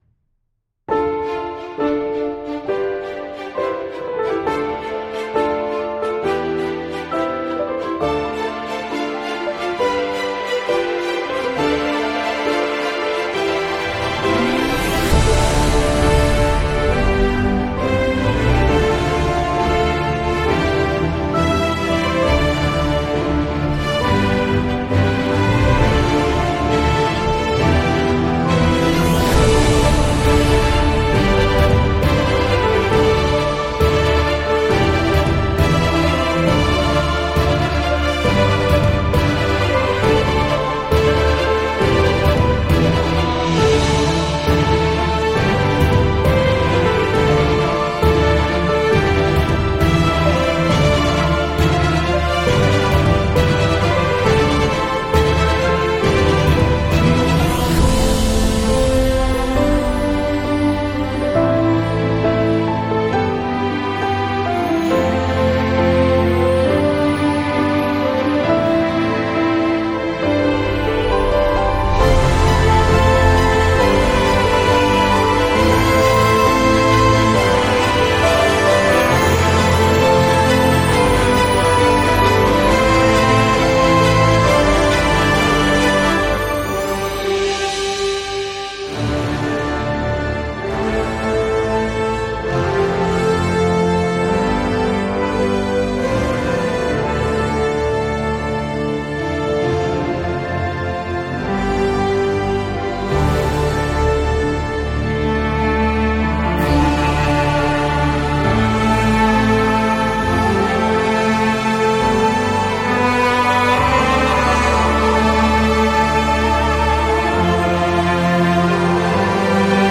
This is an epic cinematic orchestral instrumental.